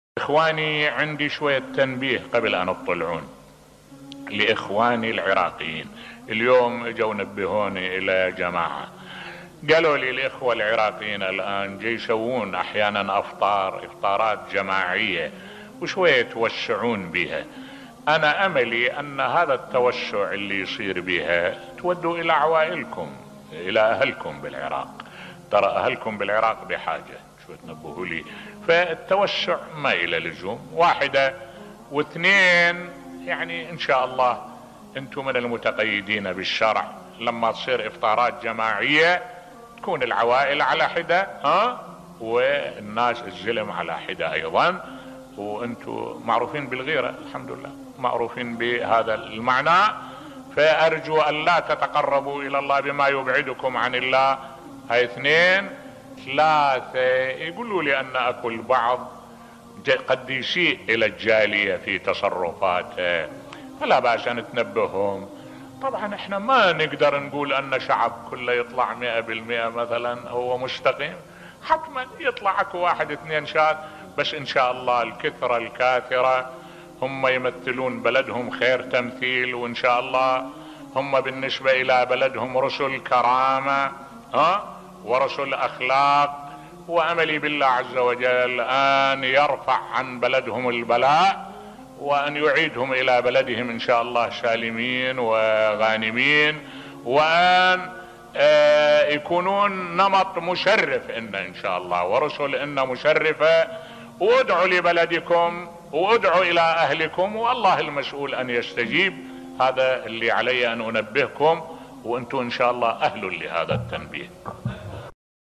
ملف صوتی توصيات ذهبية بصوت الشيخ الدكتور أحمد الوائلي